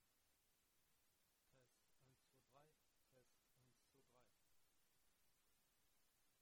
Mikrofon ton kommt ein wenig von hinten?!